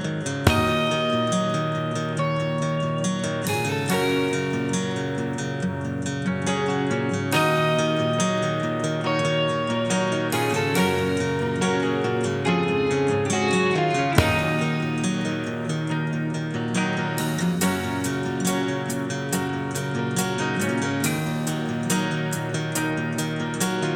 Minus Lead Guitar Rock 7:23 Buy £1.50